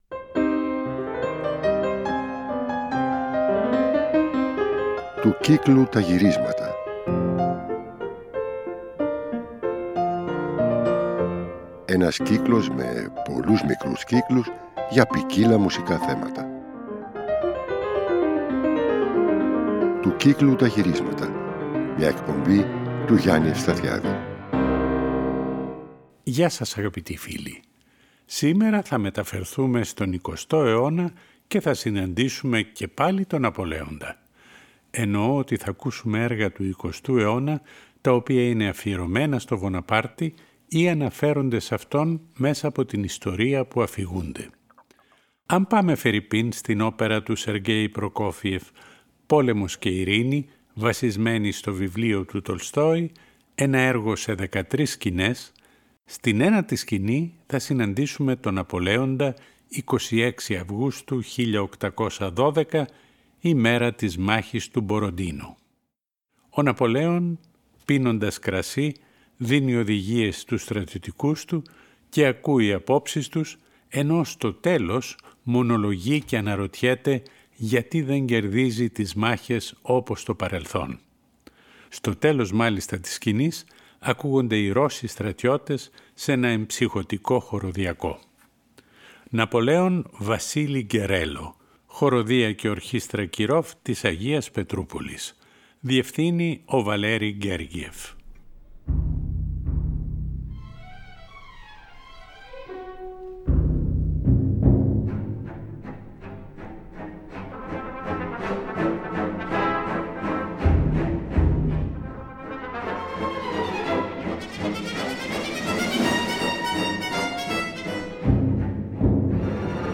για πιάνο με 4 χέρια